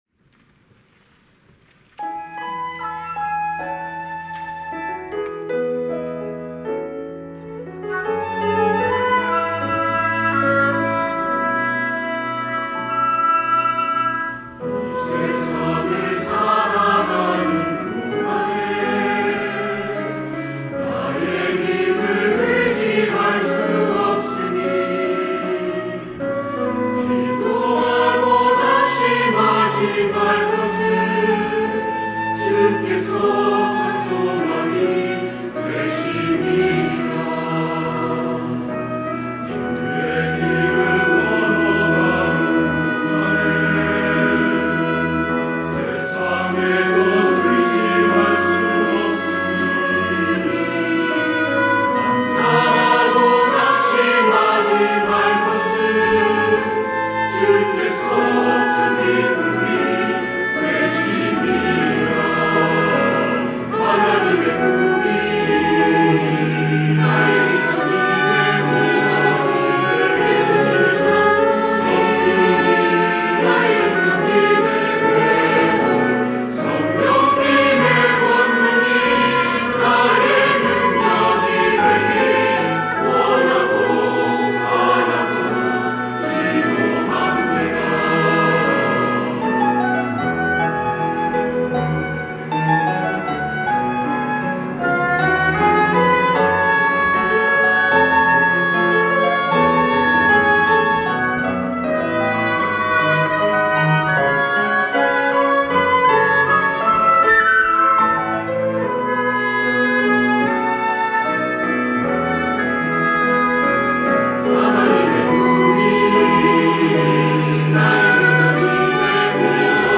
할렐루야(주일2부) - 원하고 바라고 기도합니다
찬양대